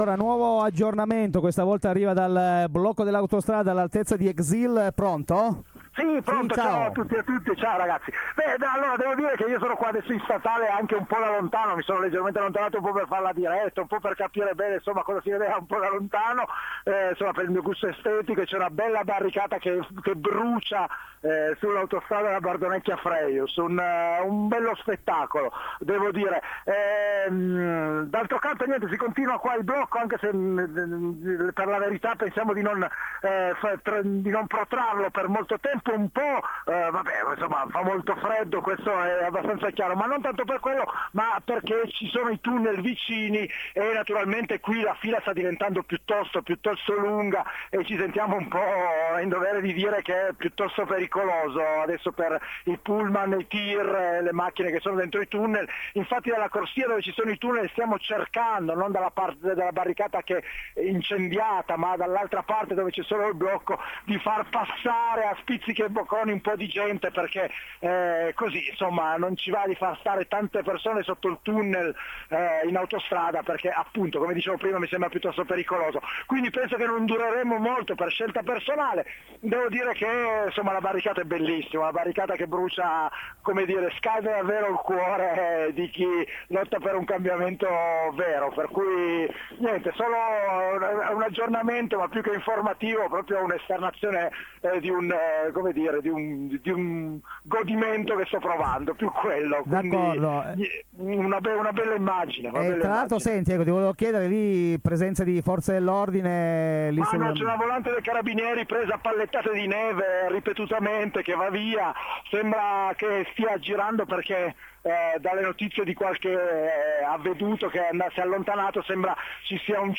–Dirette–